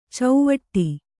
♪ cauvaṭṭi